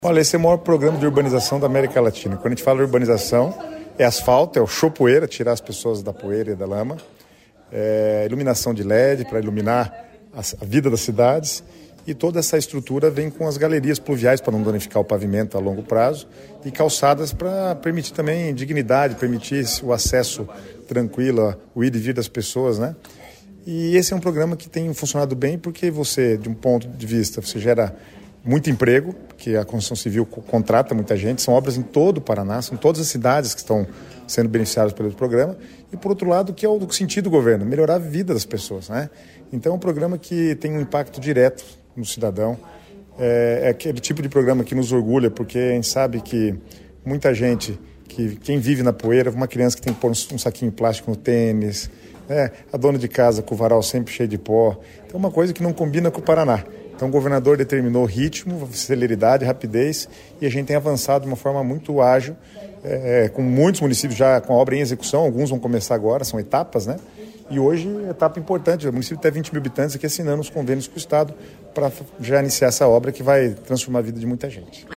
Sonora do secretário Estadual das Cidades, Guto Silva, sobre as liberações desta terça pelo Asfalto Novo, Vida Nova